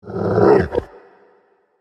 Вы можете скачать или слушать онлайн тревожные и необычные аудиоэффекты в формате mp3.